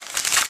Ramassage.mp3